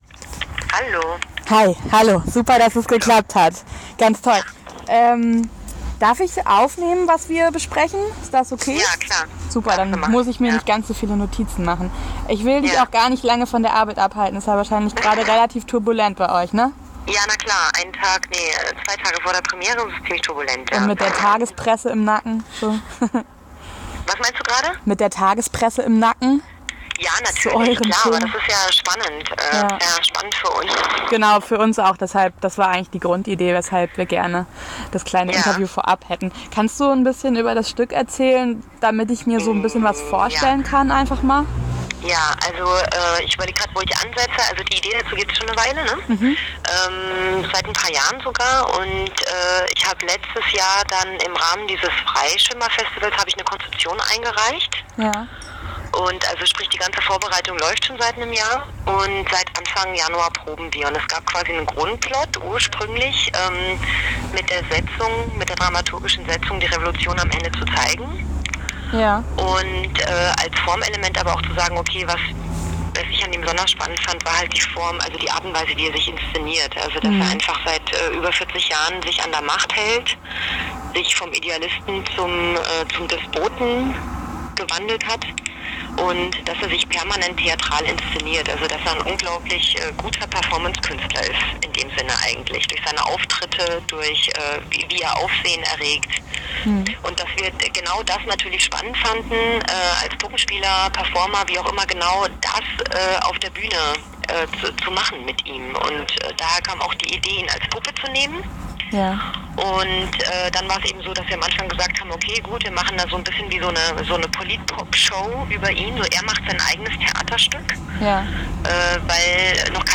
Schönschrift: Ich will dich gar nicht lange von der Arbeit abhalten.